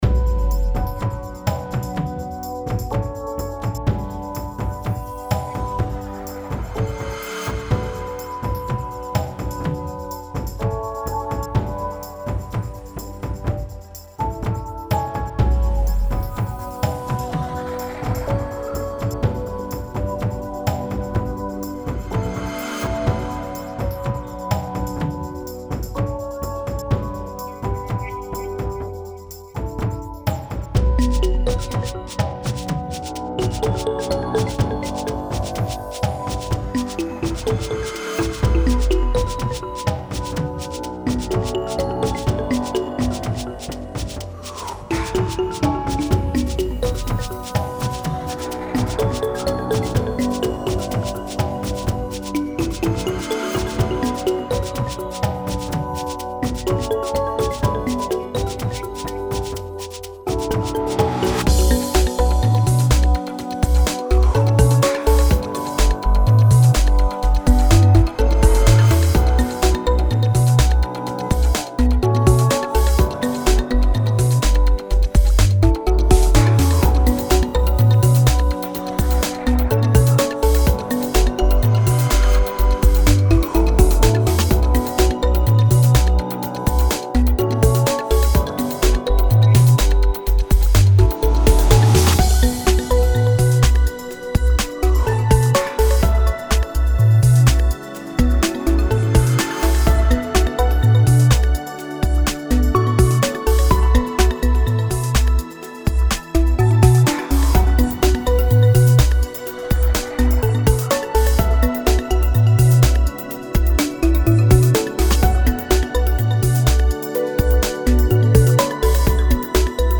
Long 4 minute drum and bass chill tune.